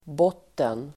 Uttal: [b'åt:en]